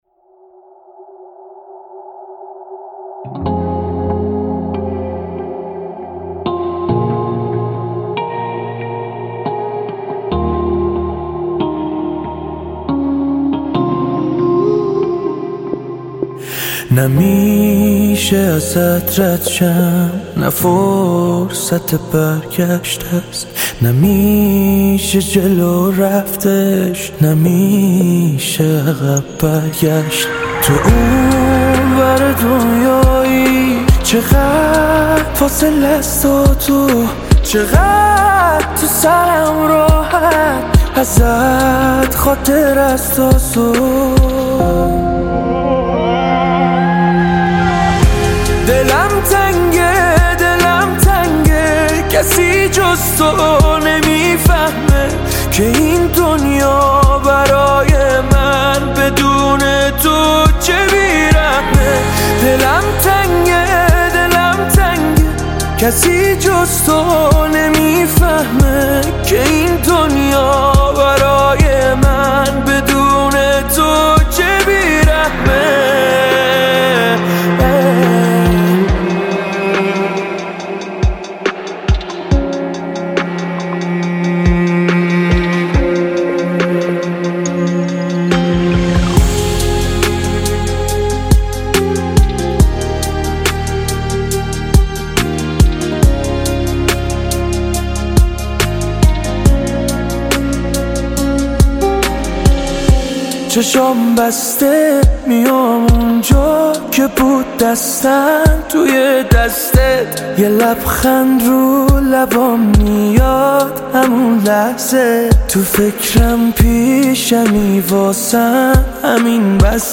پاپ عاشقانه غمگین